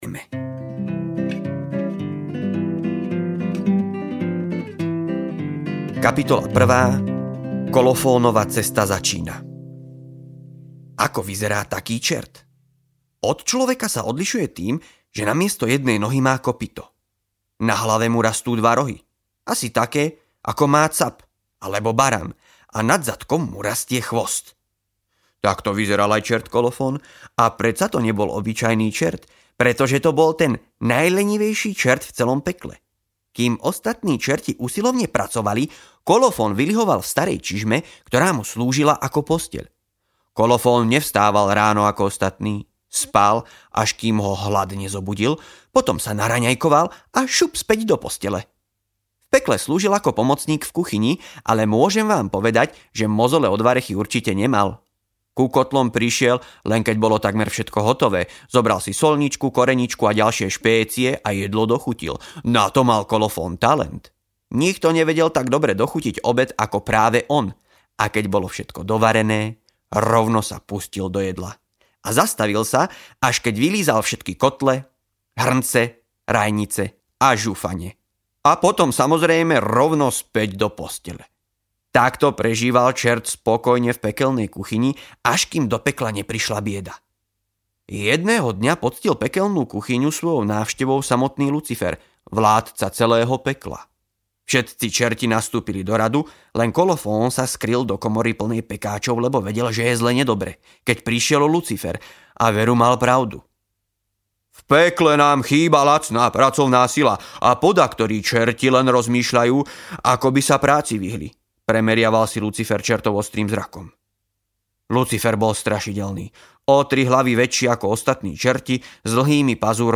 O čertovi Kolofónovi audiokniha
Ukázka z knihy